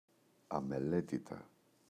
αμελέτητα, τα [ameꞋletita]